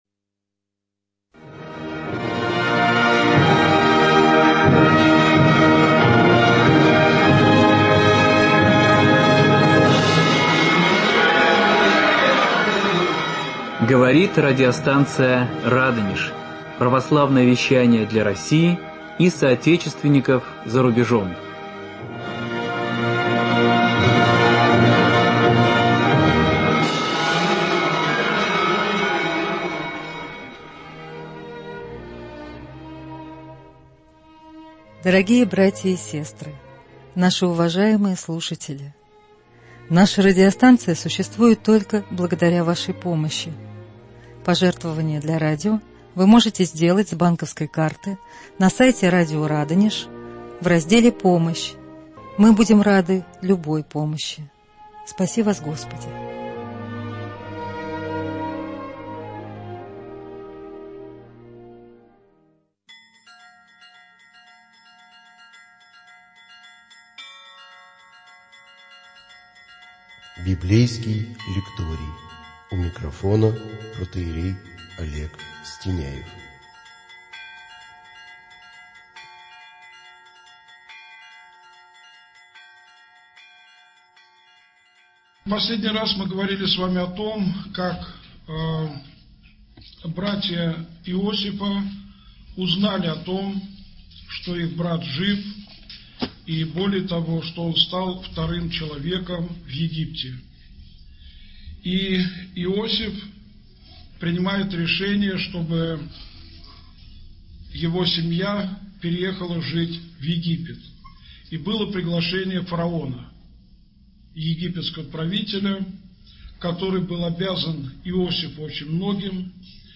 Проблемы большой семьи. Беседа 5